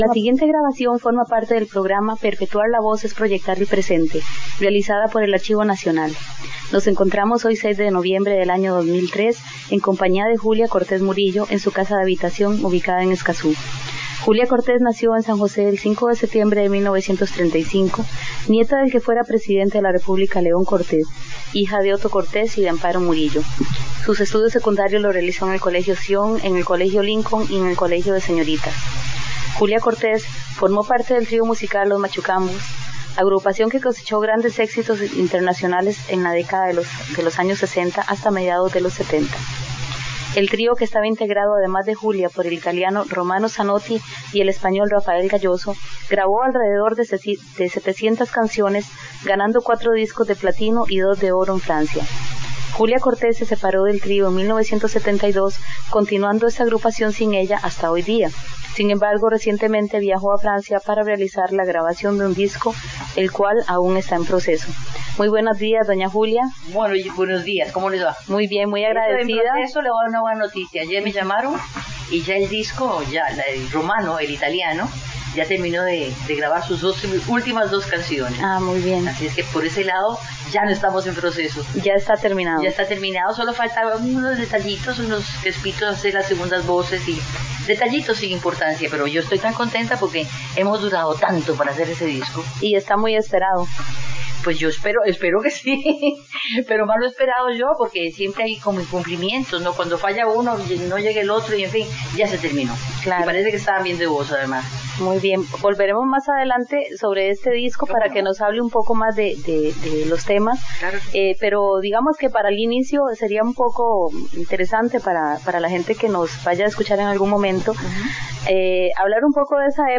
Grabación de la entrevista a Julia Cortés Murillo, cantante del Trío Los Machucambos - Archivo Nacional de Costa Rica
Notas: Casete de audio y digital